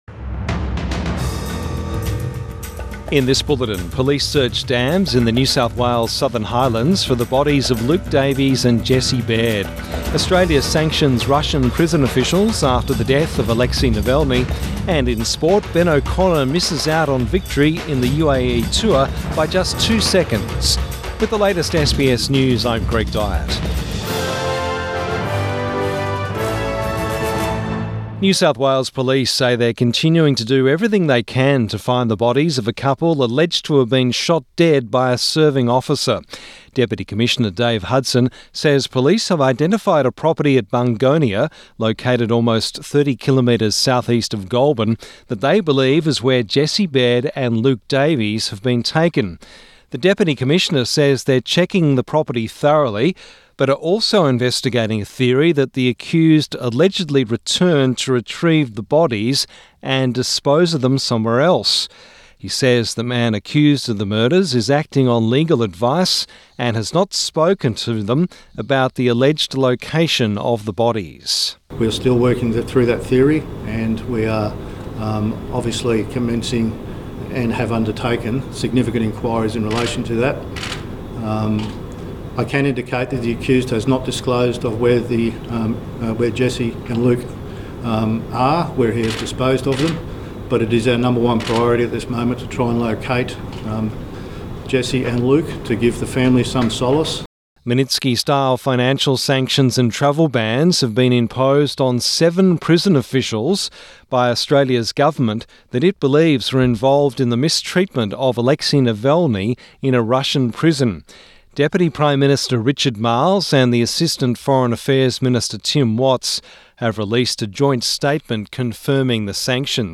Midday News Bulletin 26 February 2024